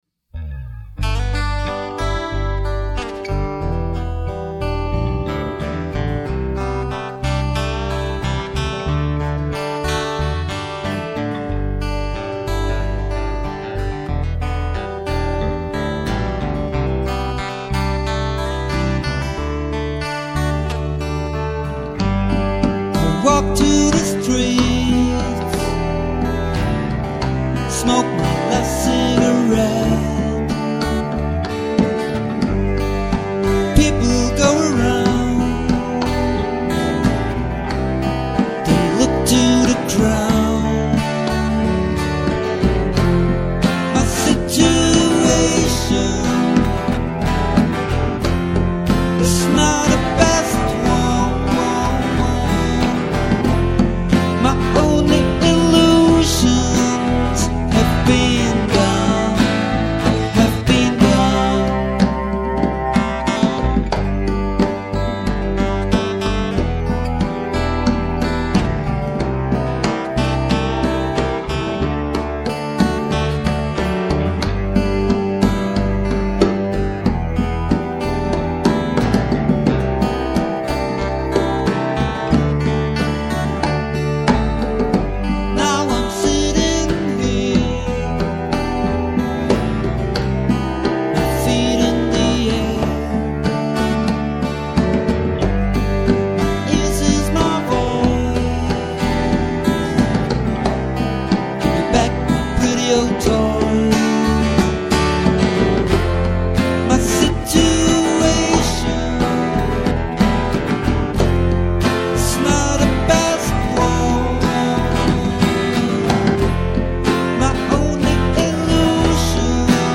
(unplugged)